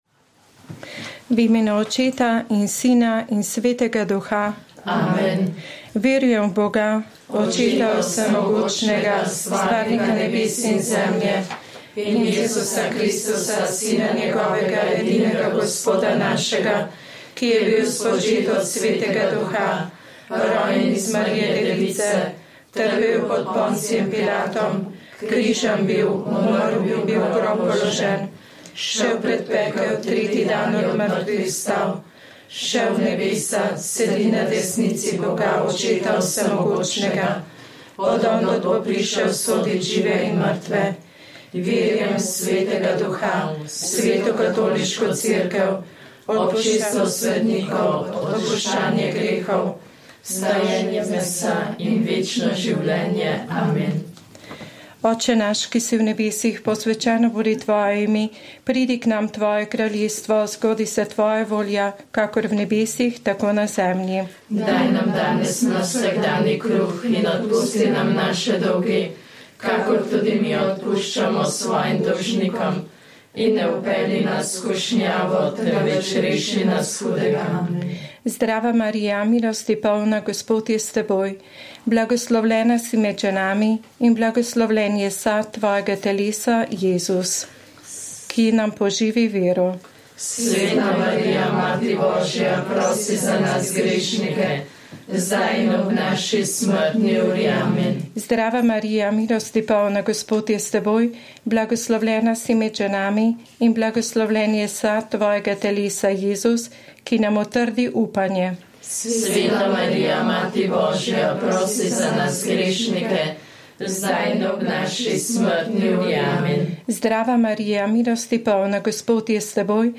Rožni venec
Molile so redovnice - Hčere Marije Pomočnice.